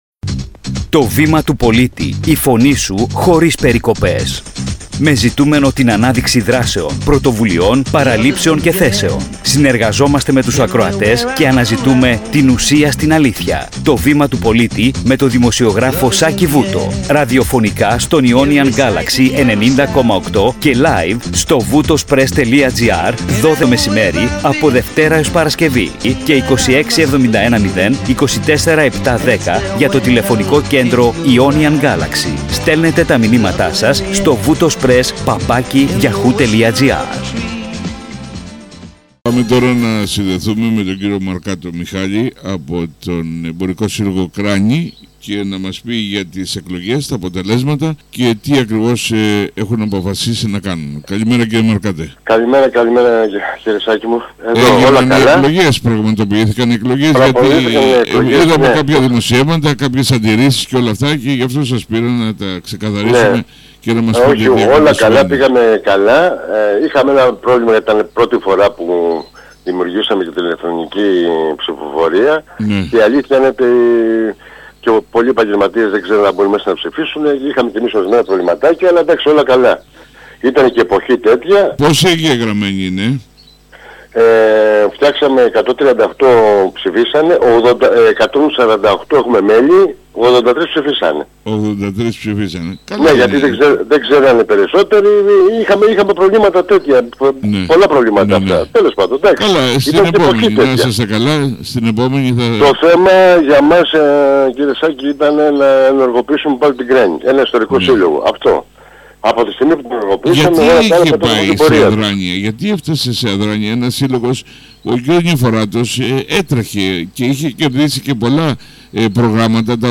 Ρεπορτάζ